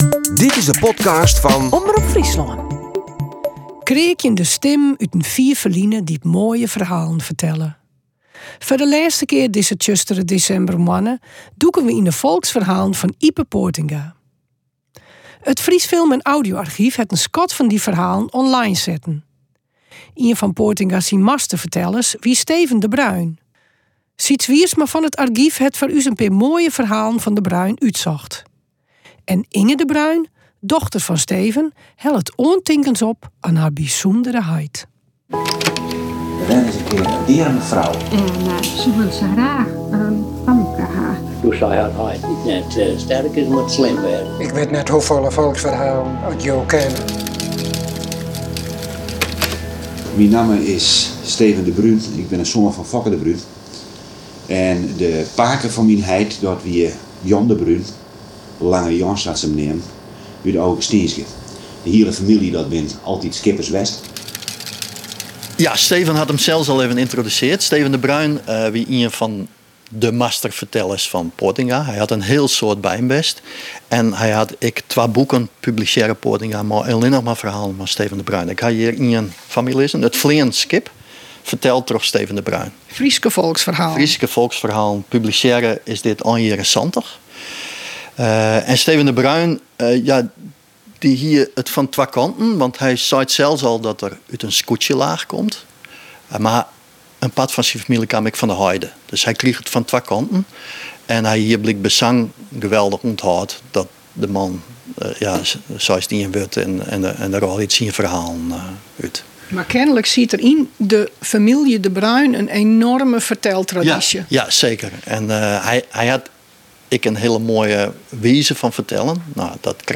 Kreakjende stimmen út in fier ferline dy't moaie ferhalen fertelle.